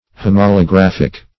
Homolographic \Hom`o*lo*graph"ic\, a. [Homo- + Gr.